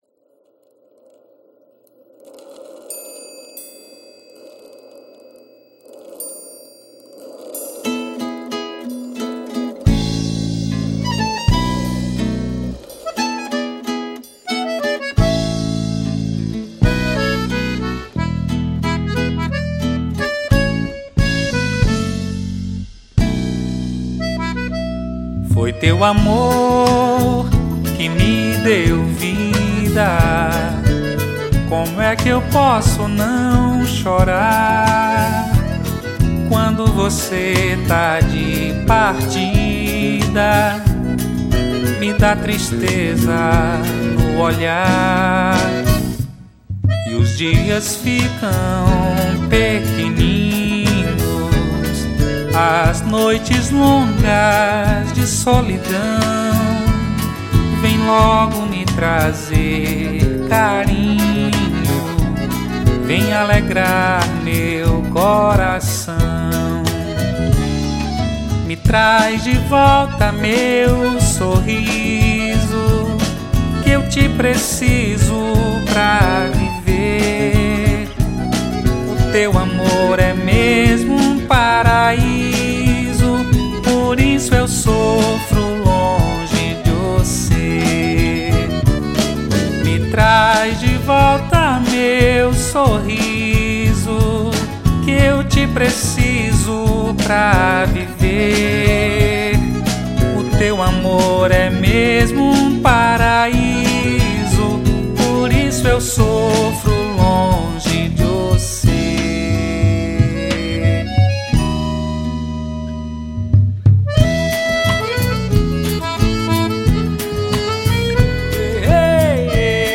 1903   04:55:00   Faixa:     Forró